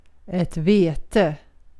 ملف تاريخ الملف وصلات معلومات الصورة (ميتا) Sv-ett_vete.ogg  (Ogg Vorbis ملف صوت، الطول 1٫8ث، 91كيلوبيت لكل ثانية) وصف قصير ⧼wm-license-information-description⧽ Sv-ett vete.ogg English: Pronunciation of ett vete in Swedish. Female voice. Speaker from Gotland, Sweden.